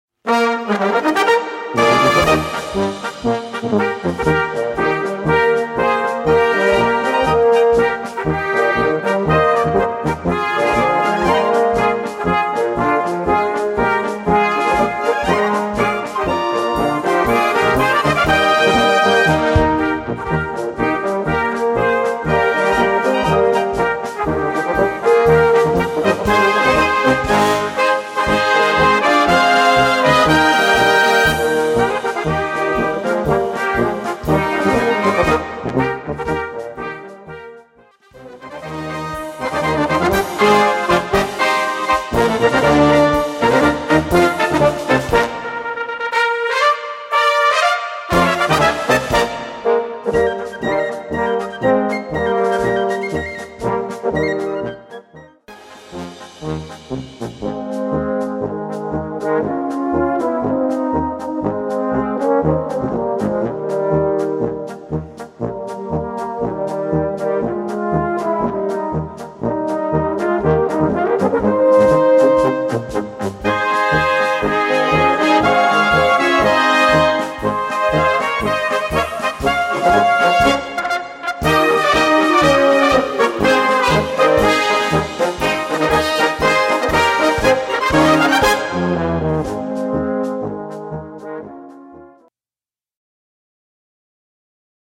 Gattung: Polka
A4 Besetzung: Blasorchester PDF